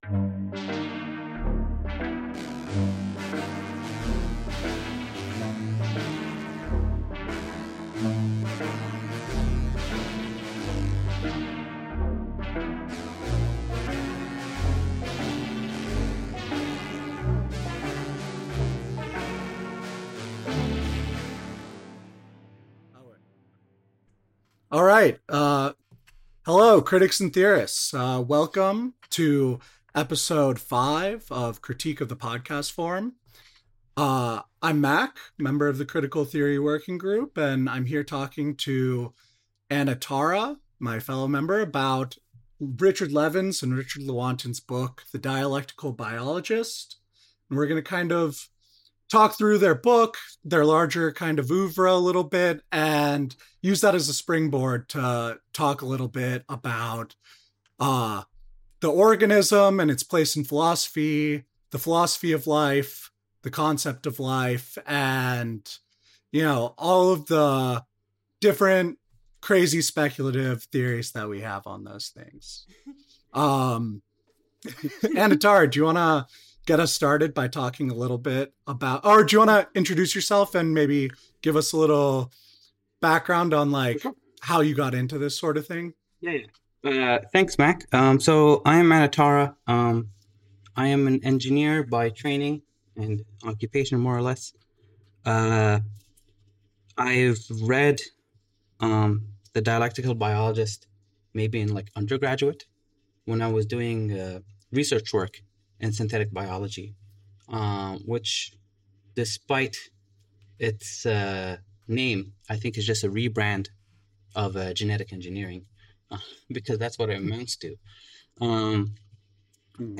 Episode 5: A Conversation About Dialectical Biology.